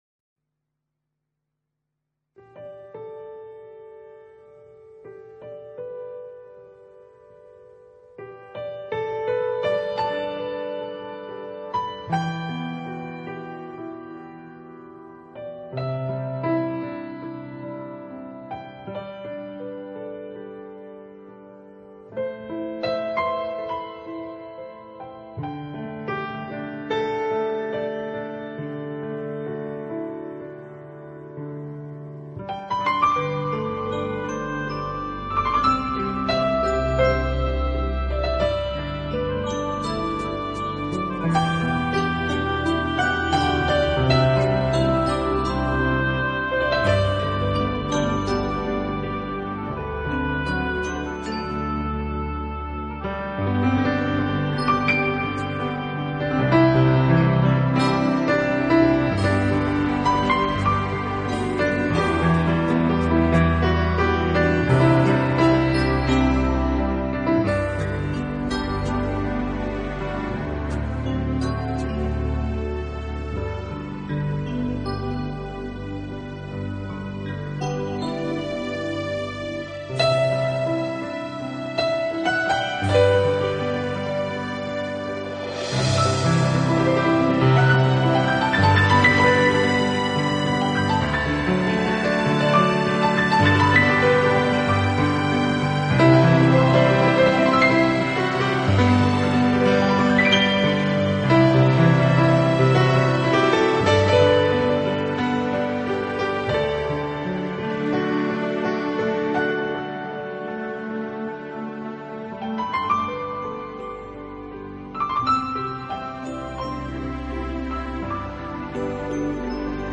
合成乐音为演奏的主体，再结合他们所采撷的大自然音效，在这两相结合之下，您可
器配置，使每首曲子都呈现出清新的自然气息。